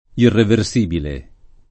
[ irrever S& bile ]